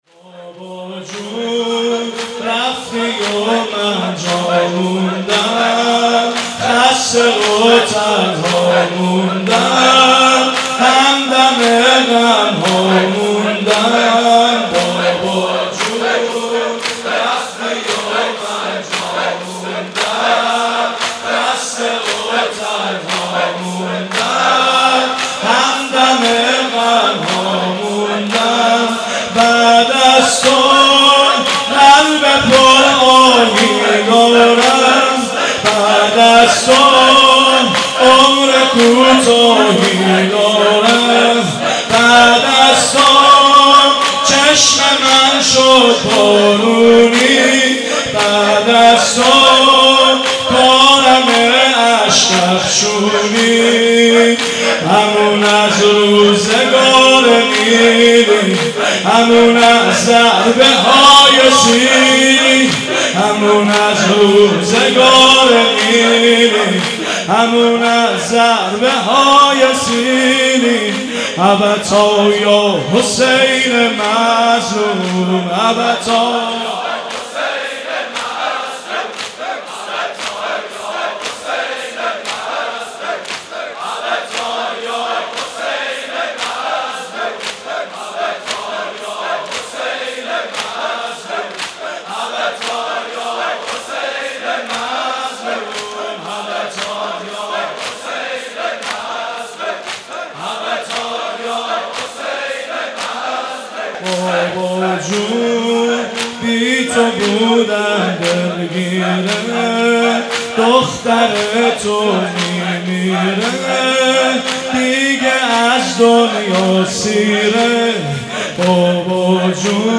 صوت مراسم شب سوم محرم ۱۴۳۷ دانشگاه امیرکبیر و حسینیه حاج همت ذیلاً می‌آید: